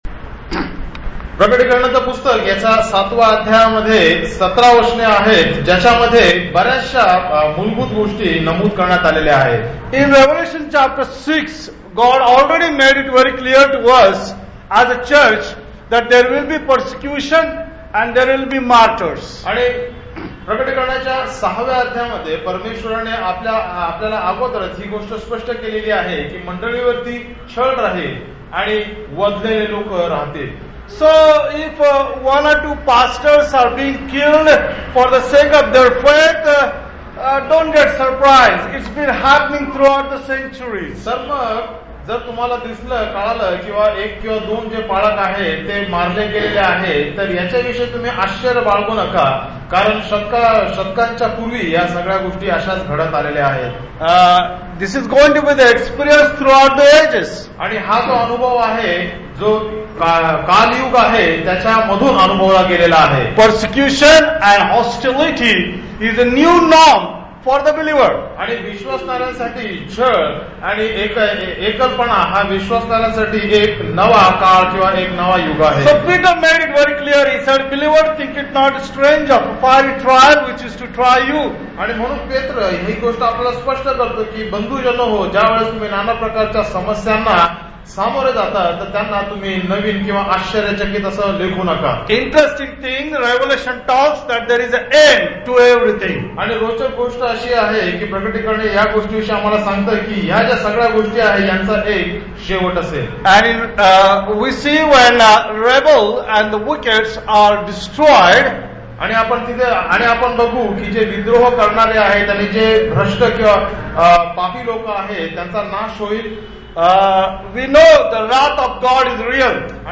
Studies in the Revelation Passage: Revelation 7:1-8 Service Type: Sunday Service Topics